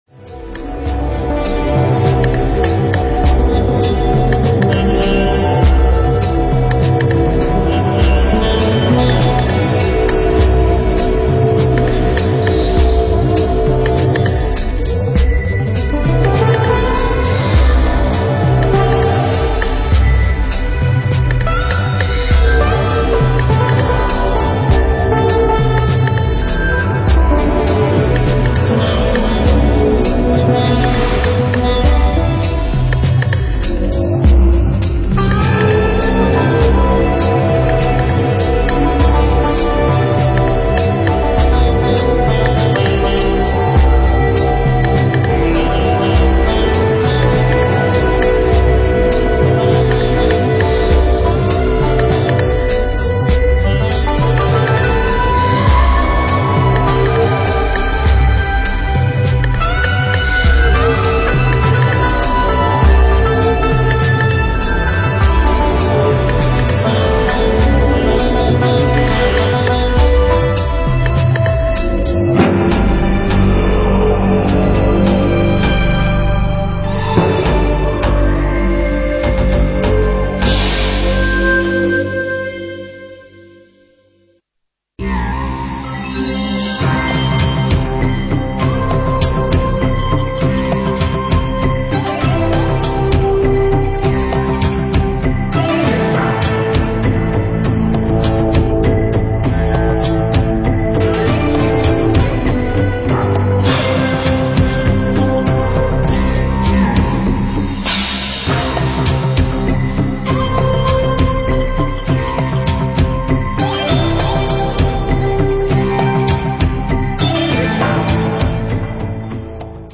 KATEGORIA: World music, muzyka ilustracyjna, filmowa.